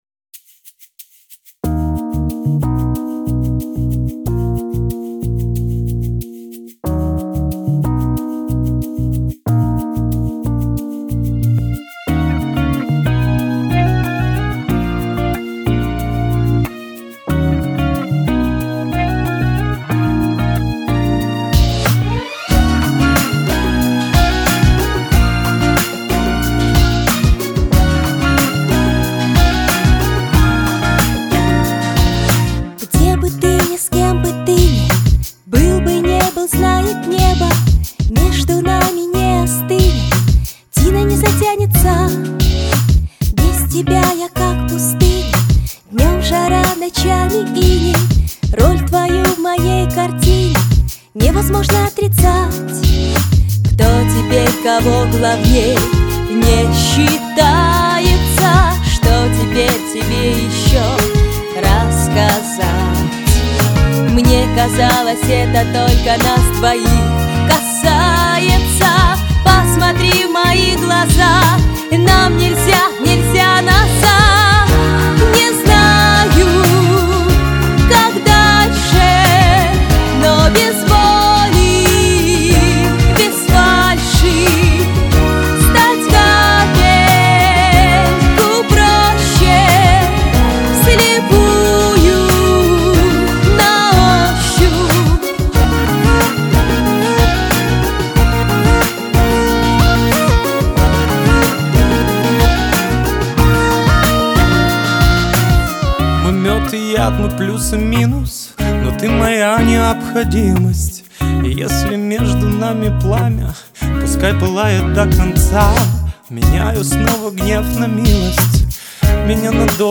Хоть и попса но слушать приятно